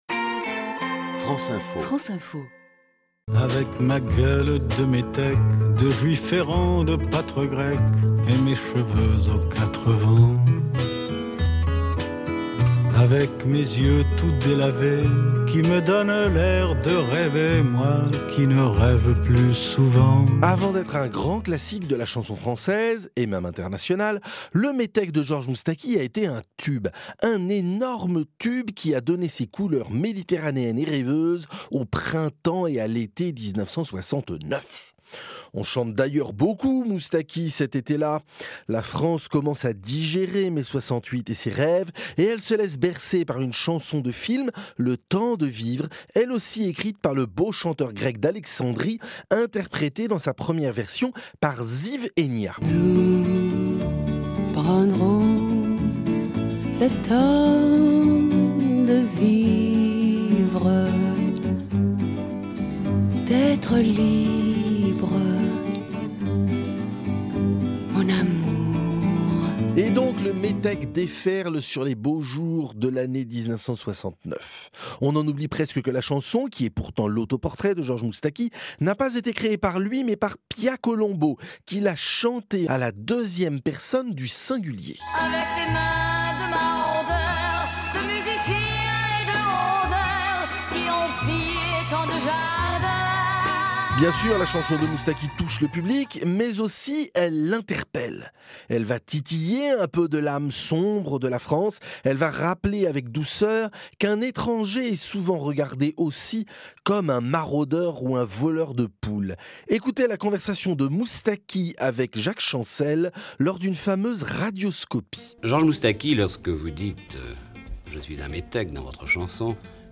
diffusées sur France Info